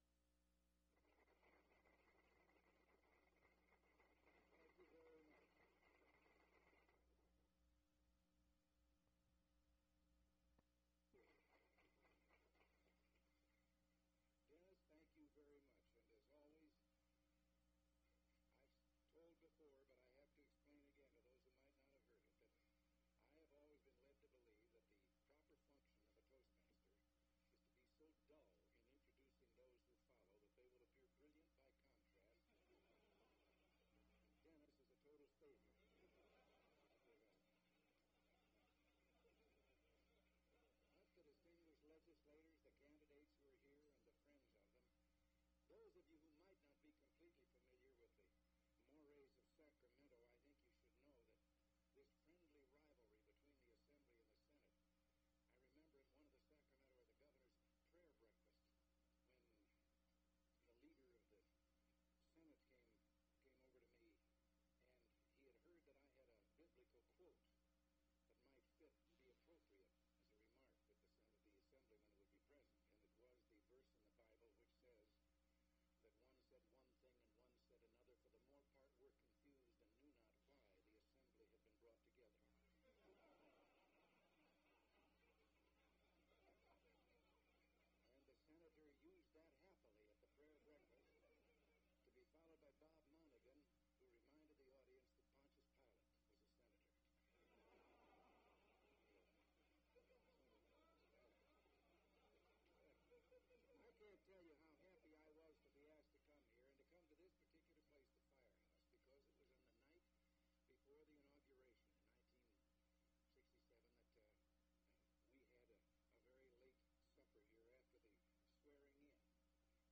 Governor Ronald Reagan Speech to Senate Republican Political Action Committee Luncheon
Audio Cassette Format.